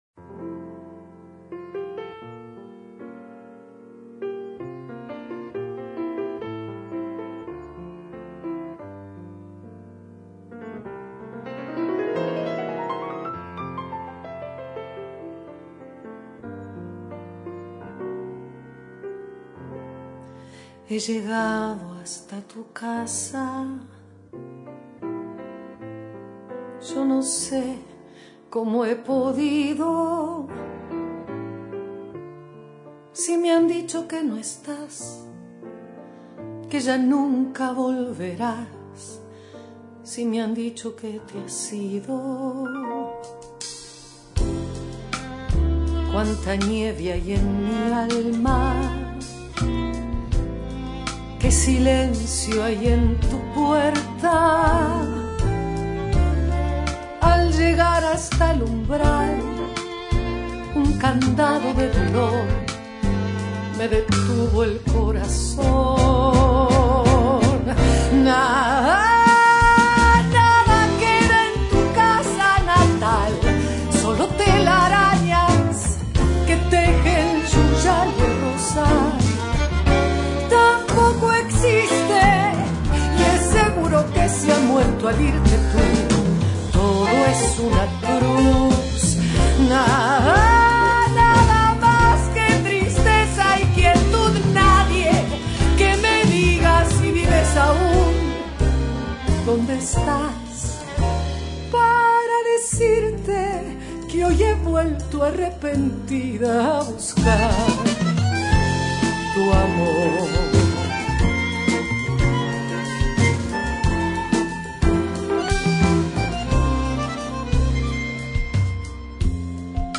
Tangos